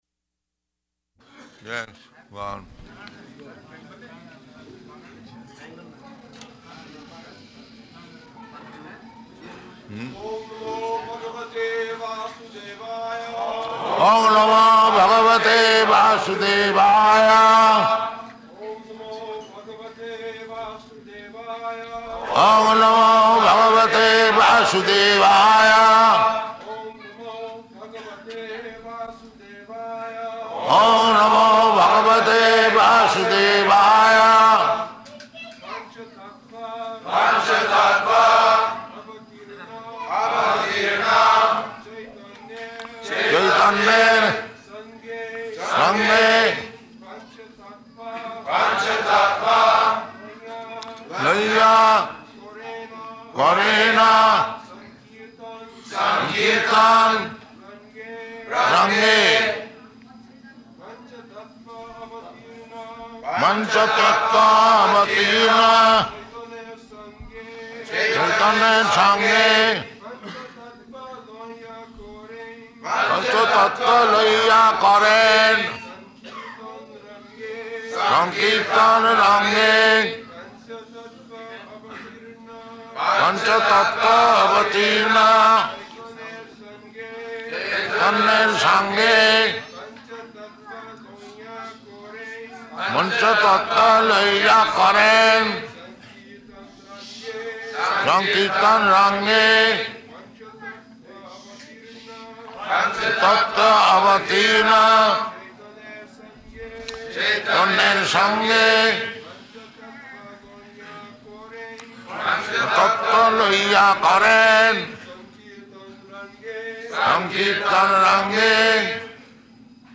Śrī Caitanya-caritāmṛta, Ādi-līlā 7.4 --:-- --:-- Type: Caitanya-caritamrta Dated: March 4th 1974 Location: Māyāpur Audio file: 740304CC.MAY.mp3 Prabhupāda: Yes.
[Prabhupāda and devotees repeat] [leads chanting of verse] pañca-tattva avatīrṇa caitanyera saṅge pañca-tattva lañā karena saṅkīrtana raṅge [ Cc.